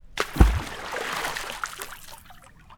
Water_16.wav